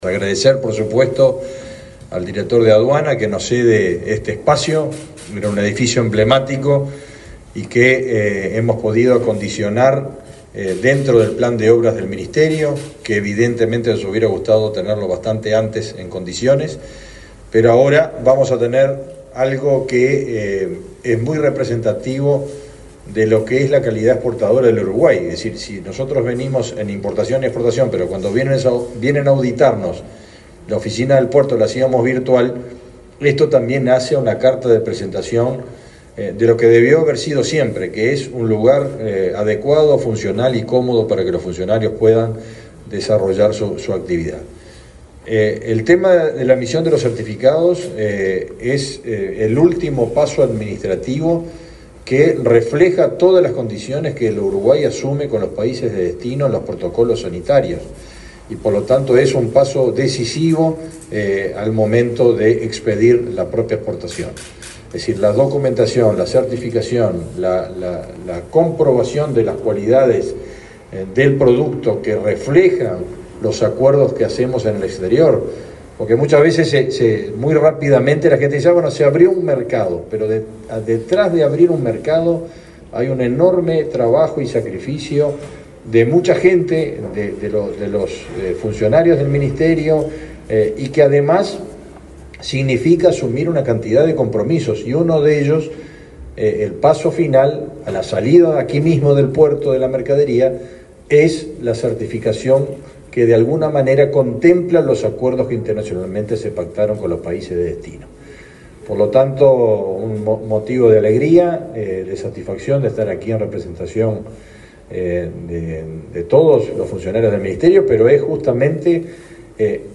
Palabras del ministro de Ganadería, Fernando Mattos
Palabras del ministro de Ganadería, Fernando Mattos 17/02/2025 Compartir Facebook X Copiar enlace WhatsApp LinkedIn El ministro de Ganadería, Fernando Mattos, participó en la inauguración de las oficinas de Sanidad Animal e Industria Animal de esa cartera en el puerto de Montevideo.